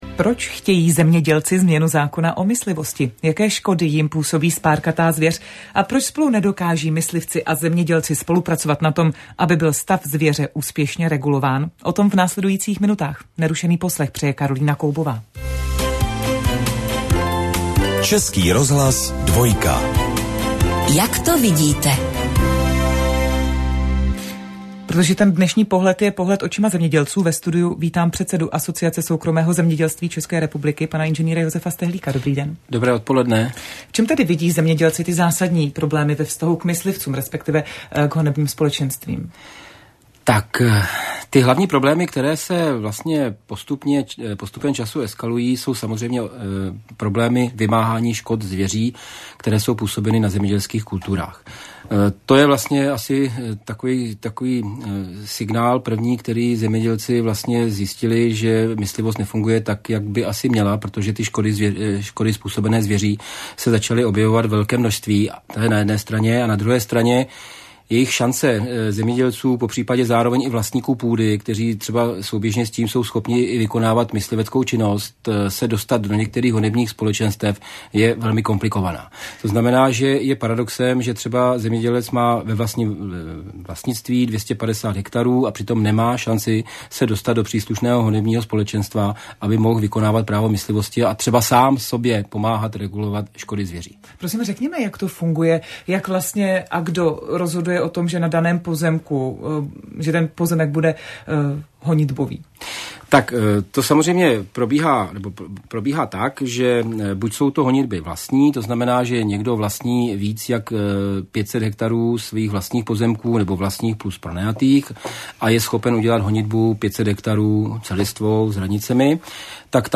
Myslivost z pohledu zemědělců – to bylo téma čtvrtečního pořadu "Jak to vidíte?" na Dvojce Českého rozhlasu